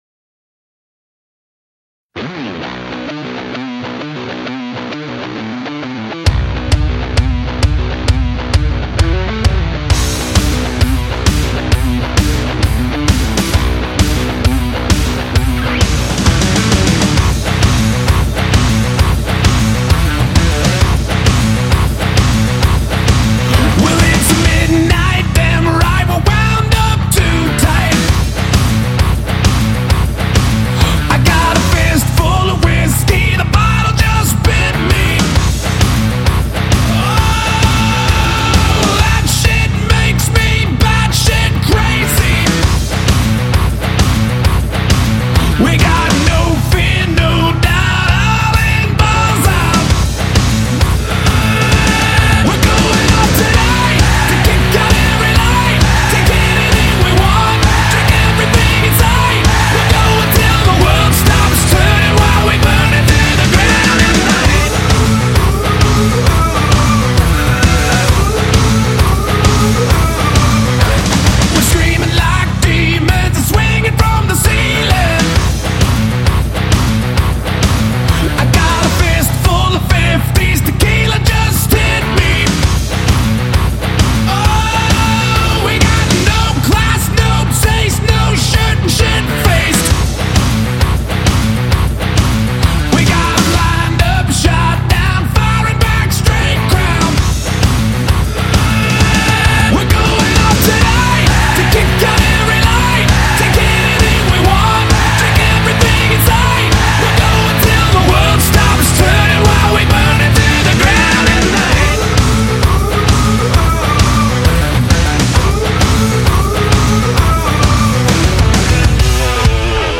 Rock 2000er